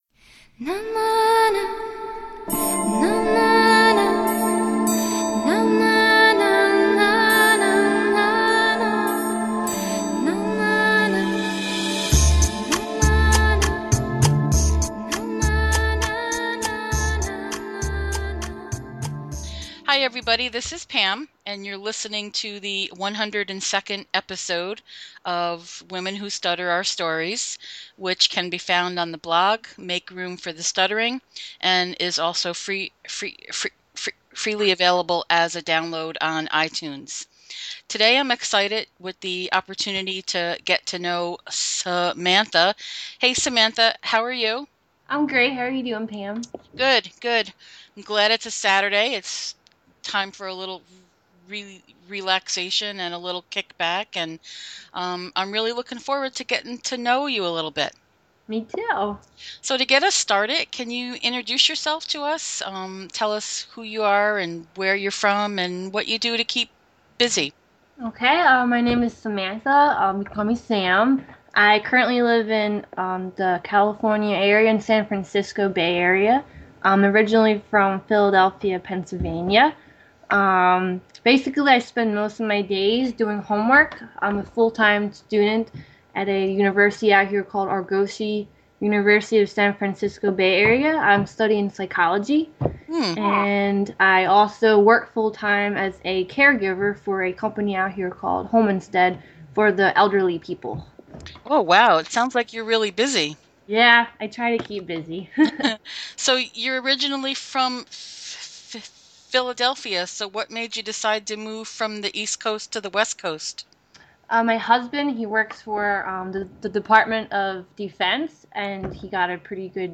This was a great conversation.